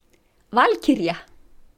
valkyrja.mp3